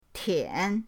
tian3.mp3